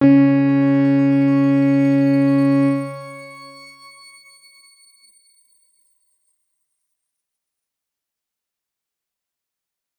X_Grain-C#3-mf.wav